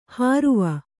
♪ hāruva